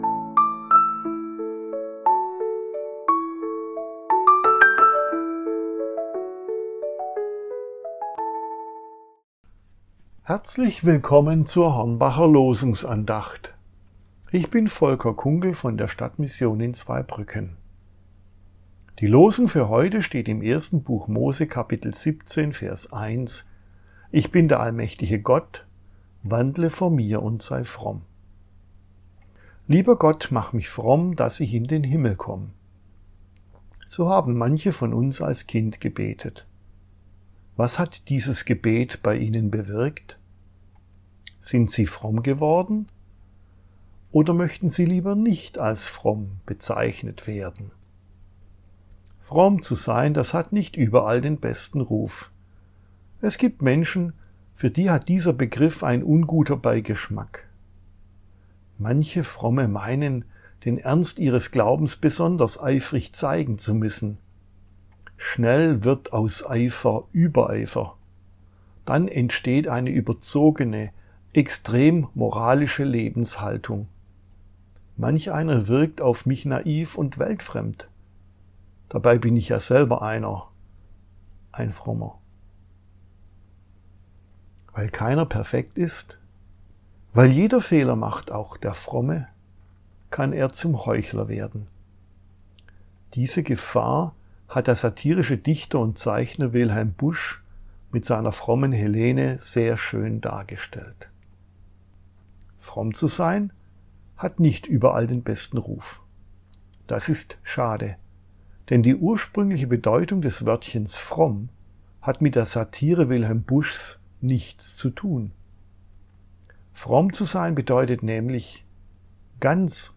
Losungsandacht für Mittwoch, 23.04.2025